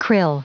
Prononciation du mot krill en anglais (fichier audio)
Prononciation du mot : krill